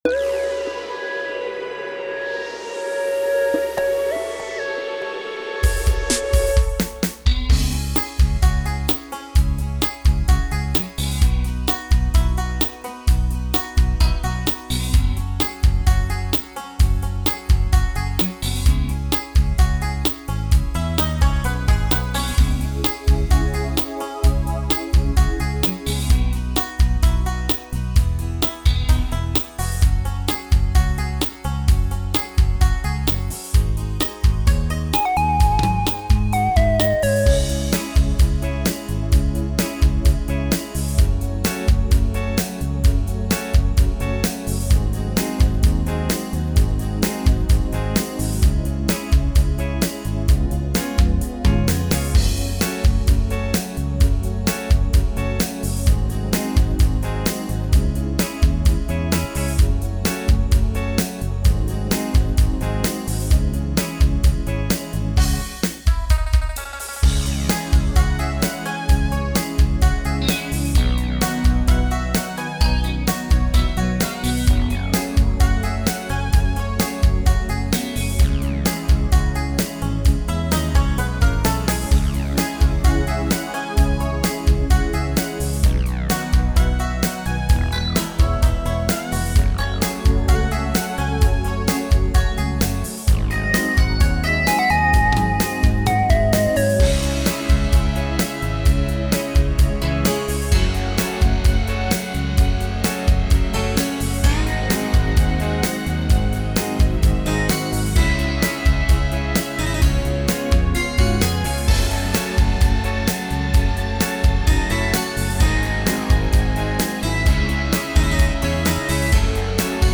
минусовка версия 240193